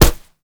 kick_soft_jab_impact_03.wav